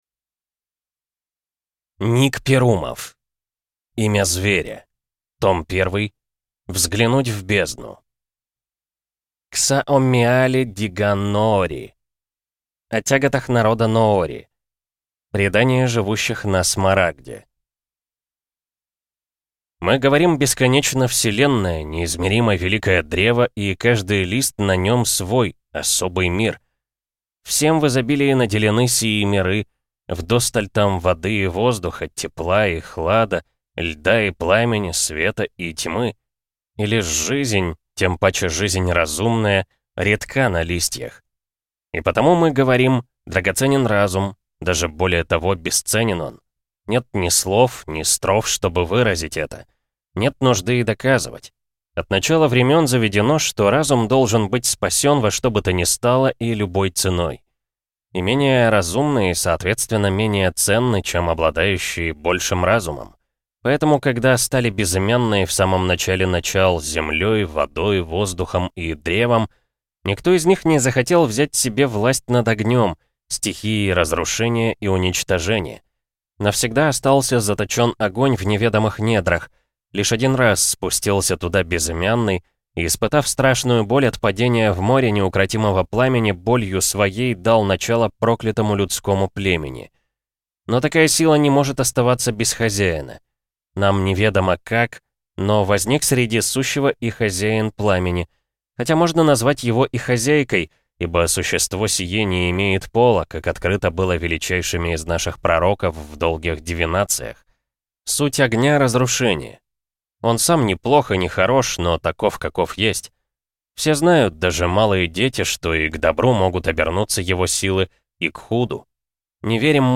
Аудиокнига Имя Зверя. Том 1. Взглянуть в бездну | Библиотека аудиокниг